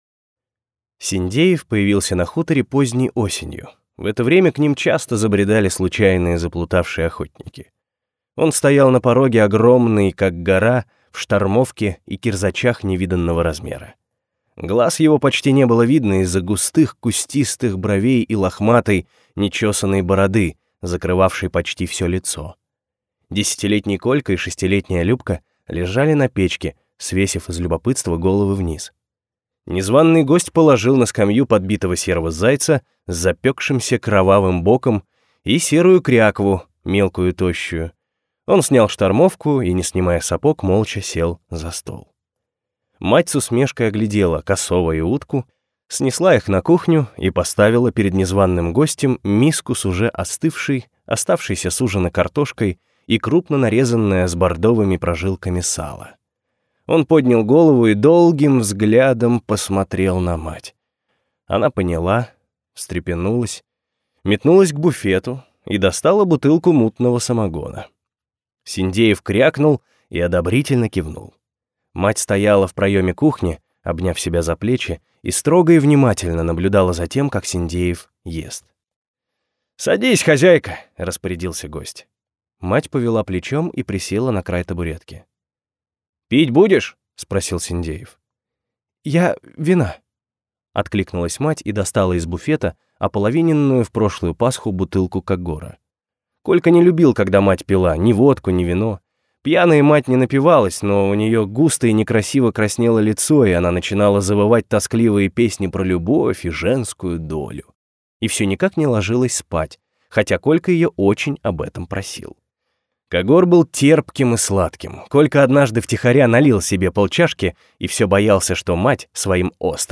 Аудиокнига Прощай навсегда!